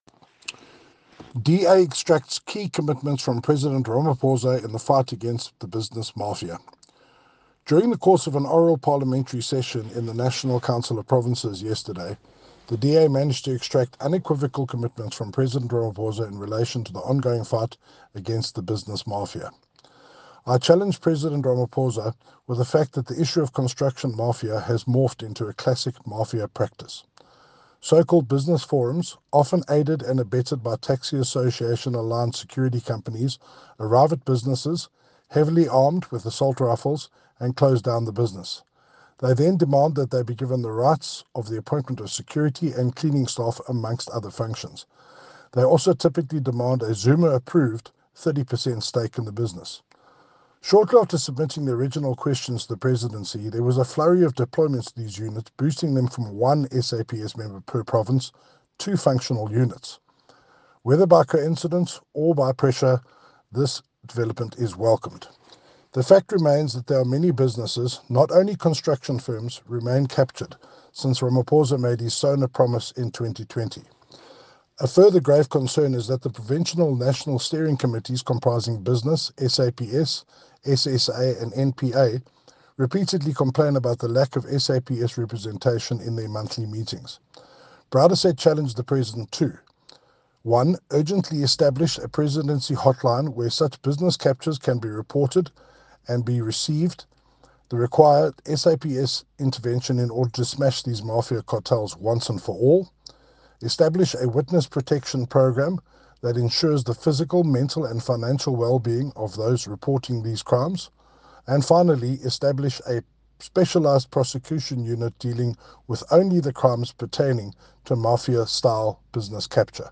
soundbite by Tim Brauteseth MP